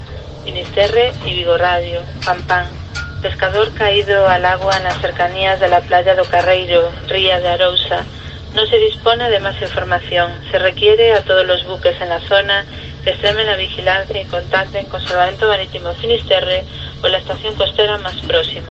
Aviso de radio de la posible desaparición en el mar de un hombre que fue a pescar a O Grove